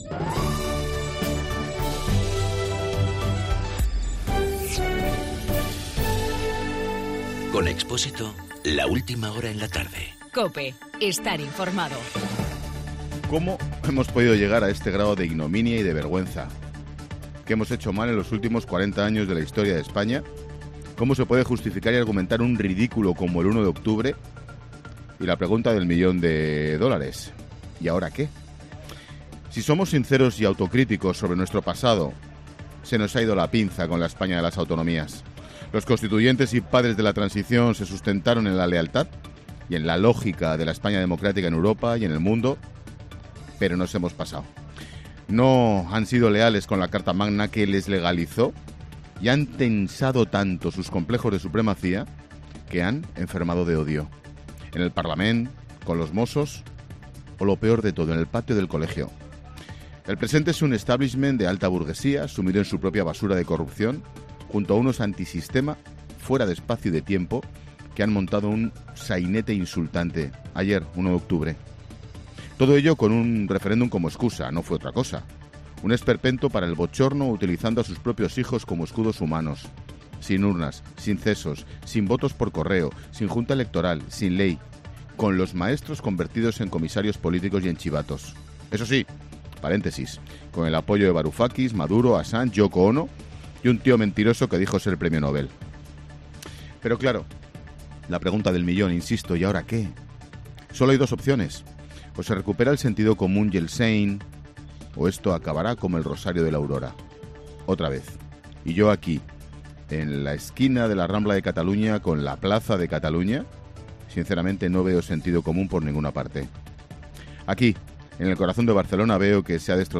Monólogo de Expósito
Ángel Expósito desde Barcelona y un día después del referéndum ilegal en Cataluña.